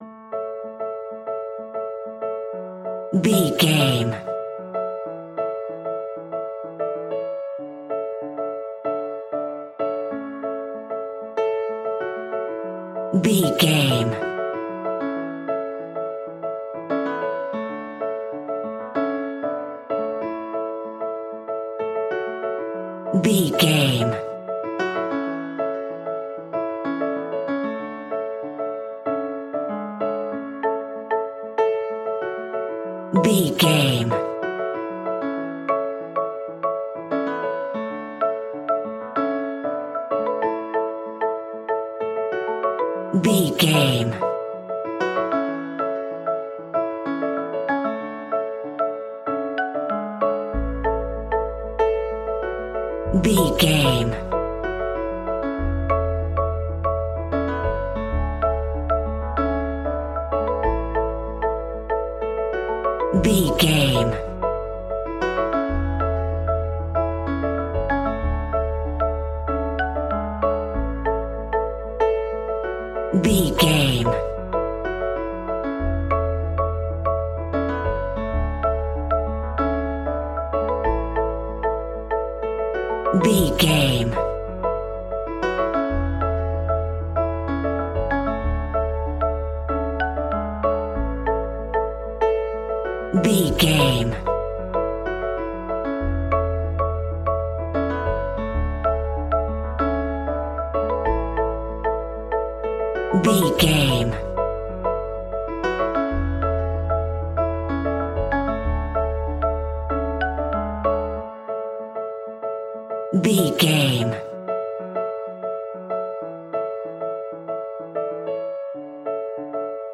Ionian/Major
pop rock
energetic
uplifting
instrumentals
indie pop rock music
upbeat
groovy
guitars
bass
drums
piano
organ